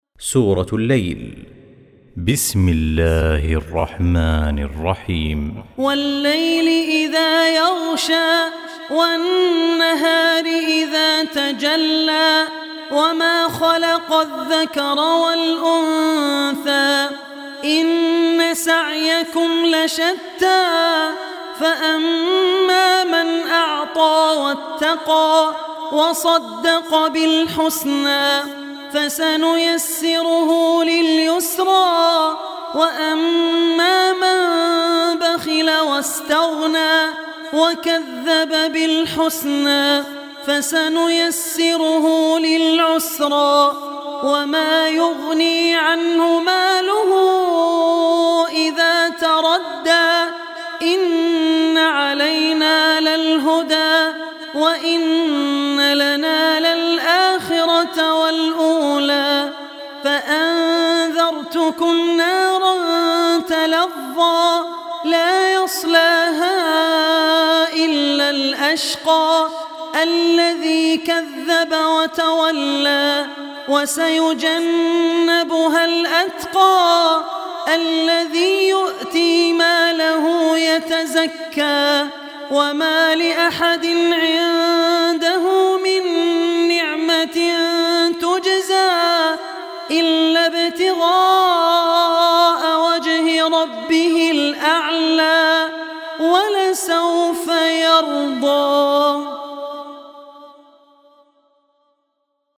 92-surah-lail.mp3